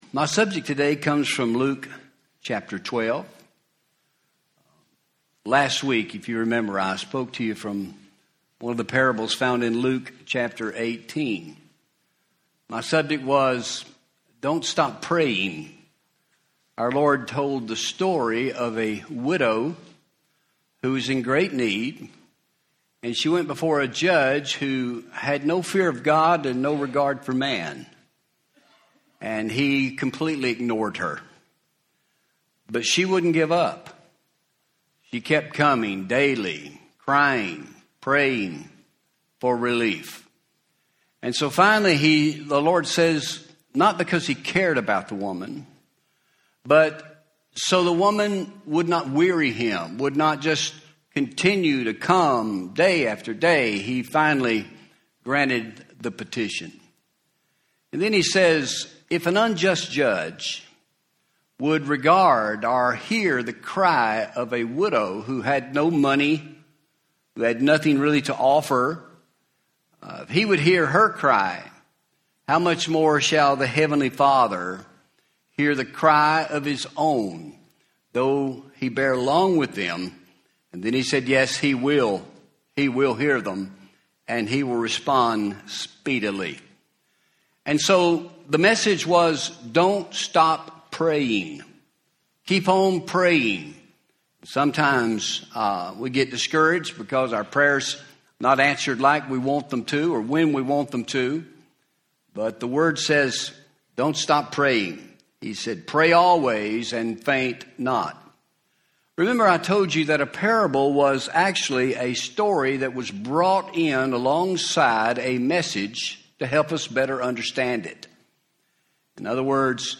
Home › Sermons › A Story of Stupidity